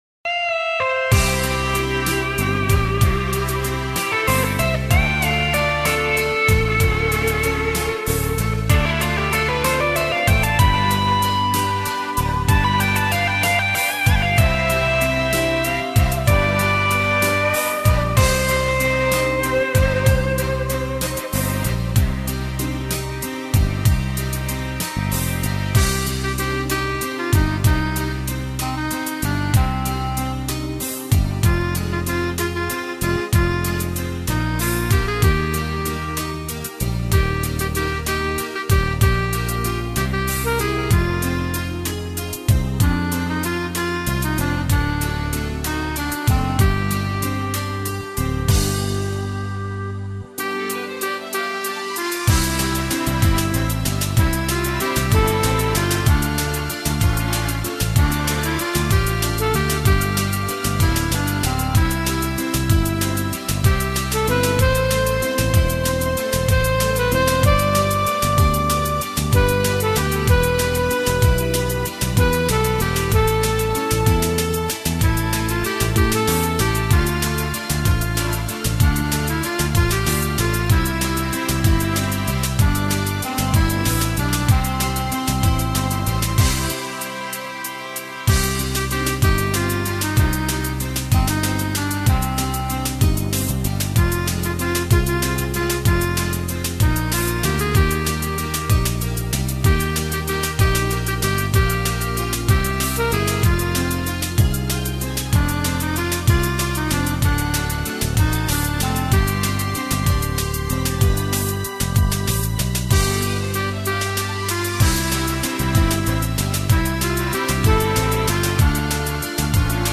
Главная » Файлы » Минусовки » минусы Қазақша